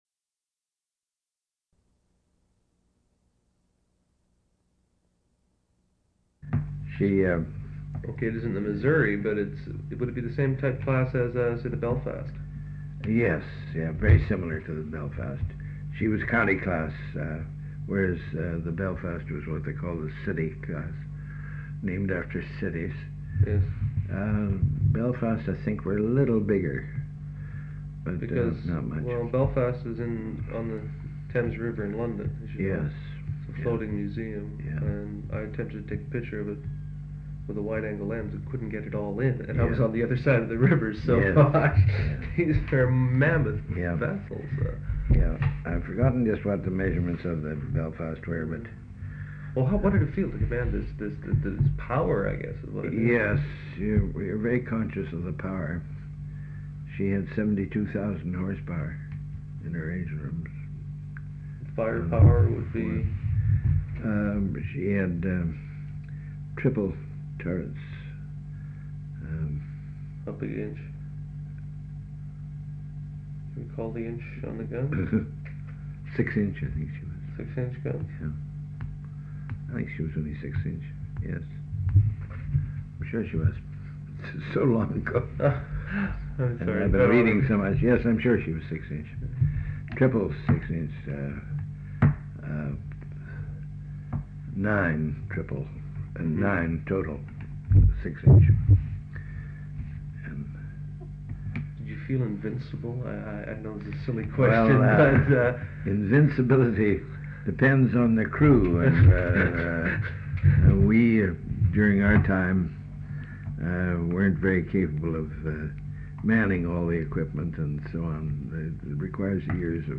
Interview 1, Reel 1, Side 1